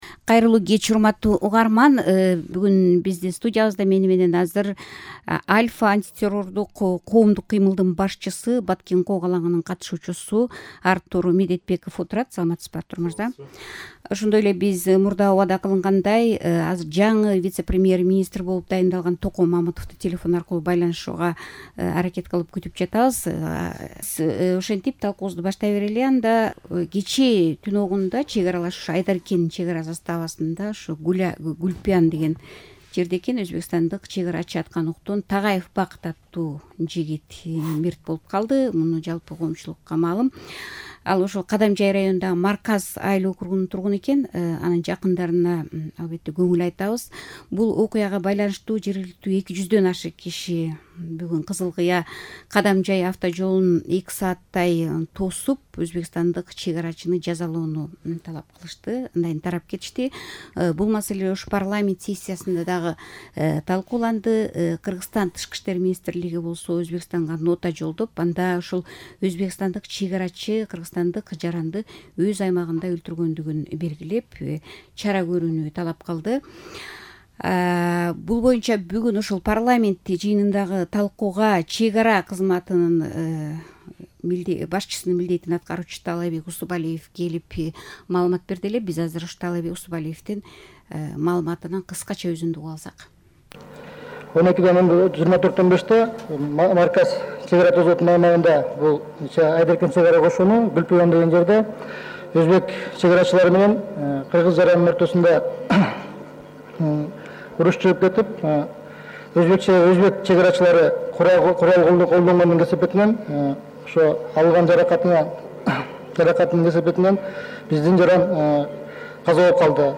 Чек арадагы кырдаал тууралуу талкуу